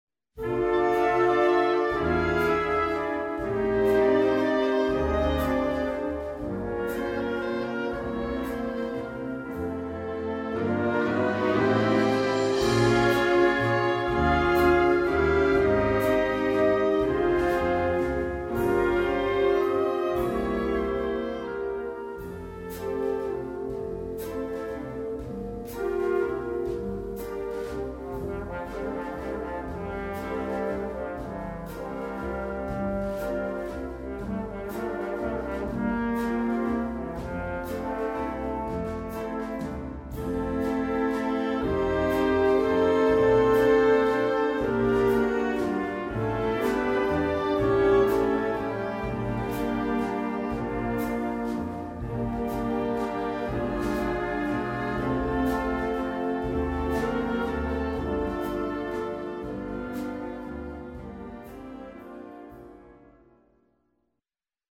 • View File Orchestre d'Harmonie